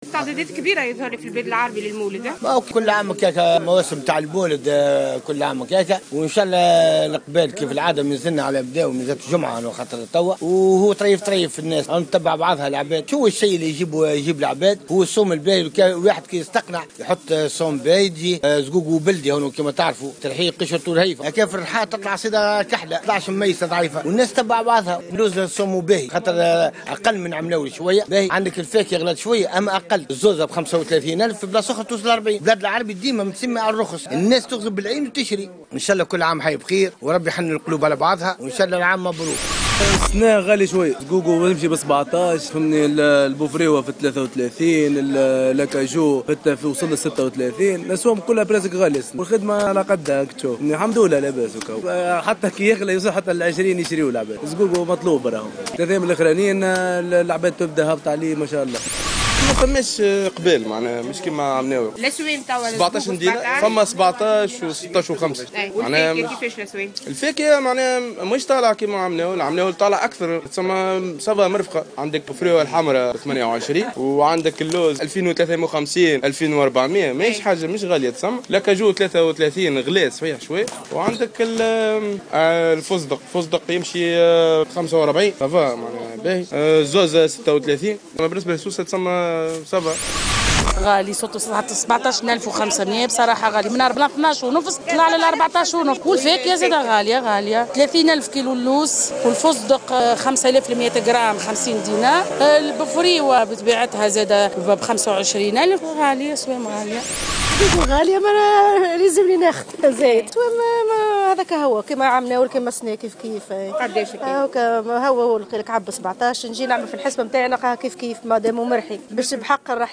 تقرير استعدادات